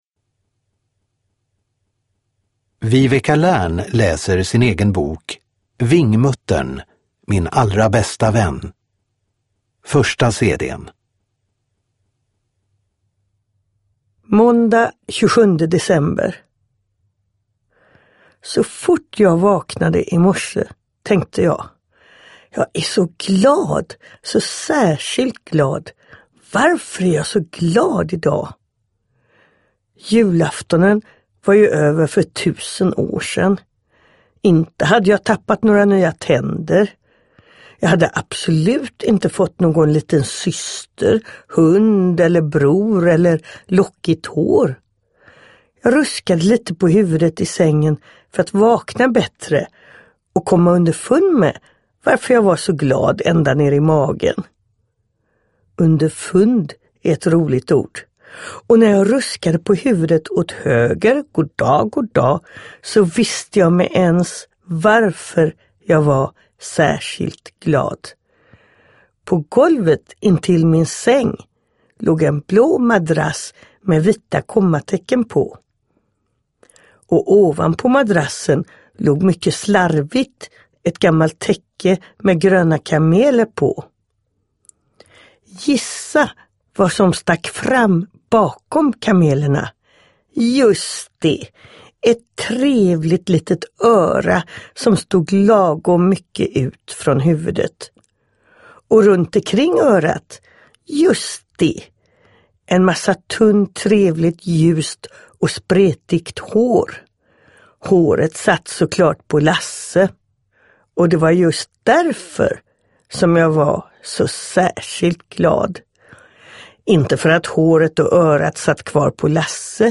Vingmuttern - min allra bästa vän – Ljudbok
Viveca Lärn läser själv boken om Vingmuttern.
Uppläsare: Viveca Lärn